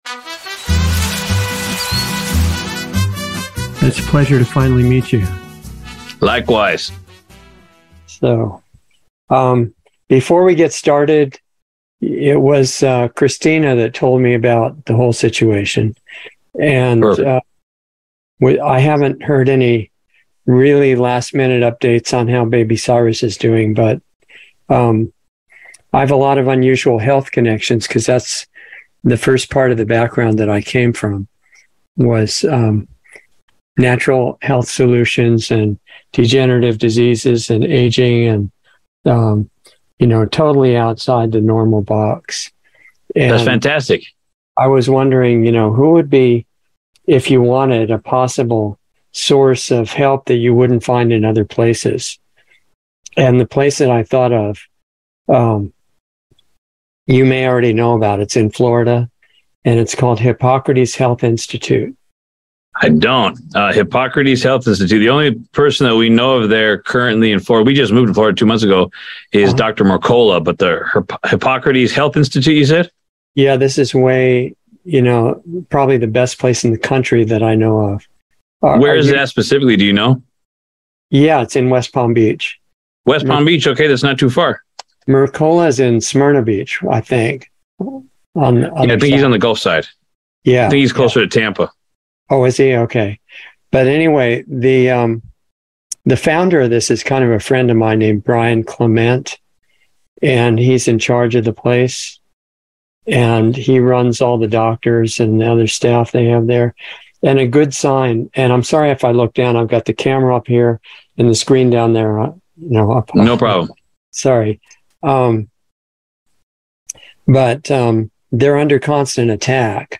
Insider Interview 8/3/22